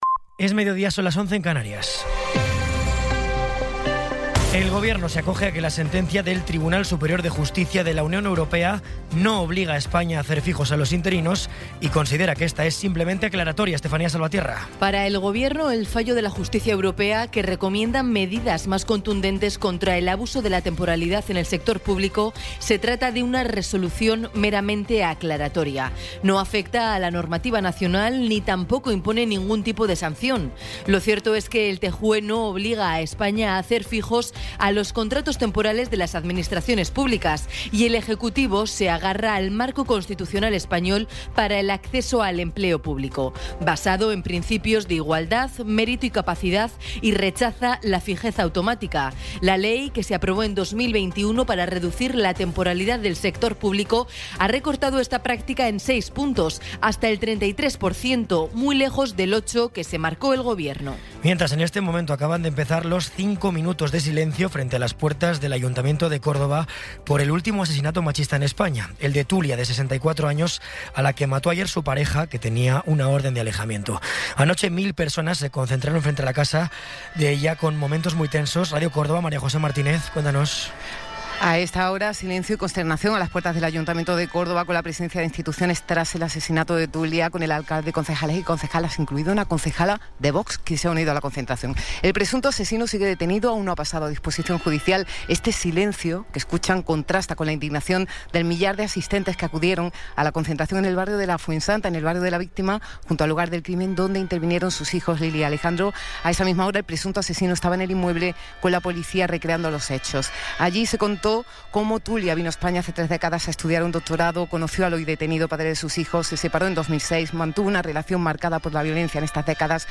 Resumen informativo con las noticias más destacadas del 14 de abril de 2026 a las doce.